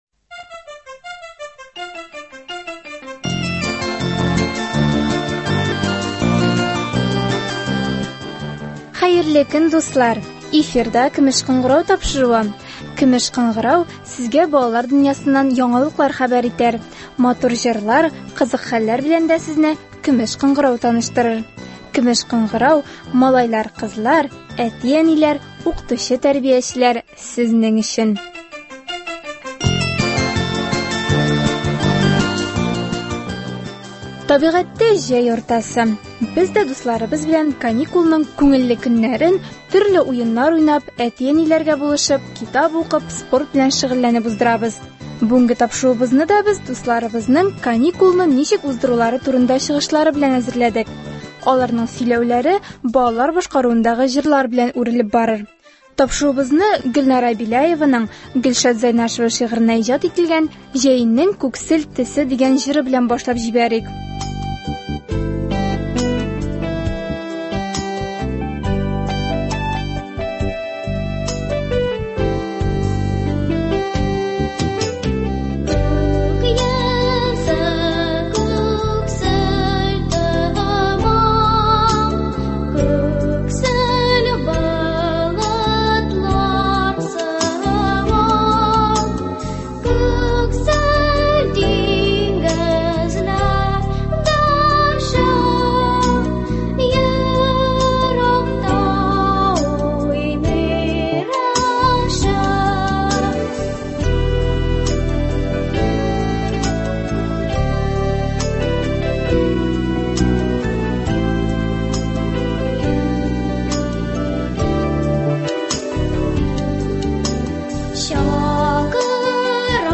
Без дә бүгенге тапшыруыбызны дусларыбызның каникулны ничек уздырулары турындагы чыгышлары белән әзерләдек. Аларның сөйләүләре балалар башкаруындагы җырлар белән үрелеп барыр.